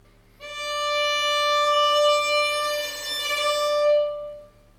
Als „Überschlagen“ bezeichne ich hier einen Klang, bei dem (bisweilen wechselnde) Obertöne dominanter als der Grundton werden. Dieser Klang ist hinsichtlich seiner „Schärfe“ ein Extremwert.
Diesen Klang kann man beispielsweise durch eine Kontaktstelle nahe beim Steg und wenig Bogendruck produzieren:
Überschlagen des Tones
ueberschlagen.mp3